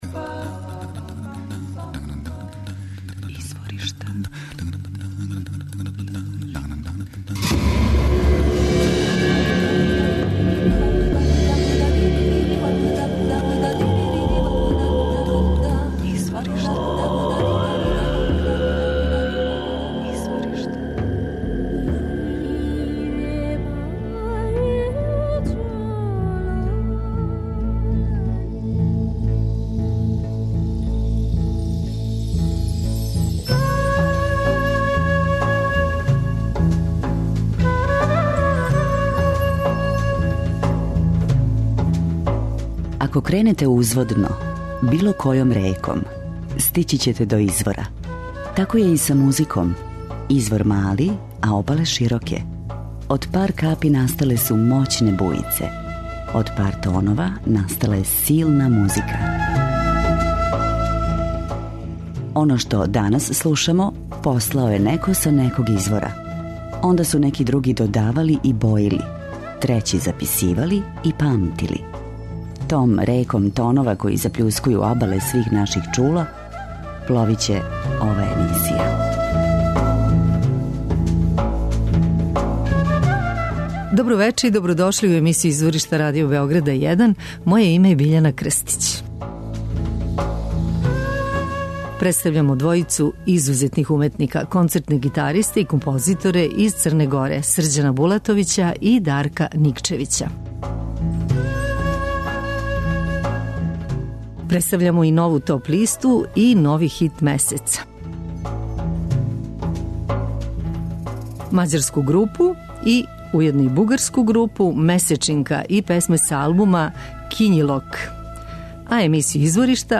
концертне гитаристе и композиторе из Црне Горе